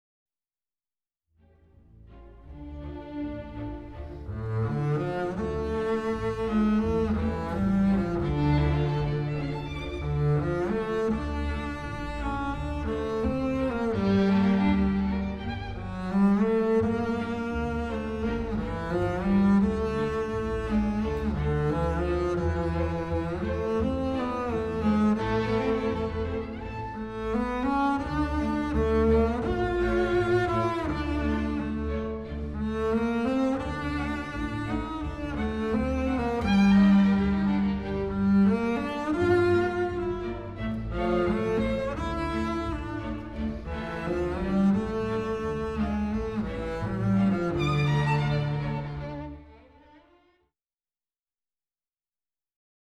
Excerpts are from the orchestra version.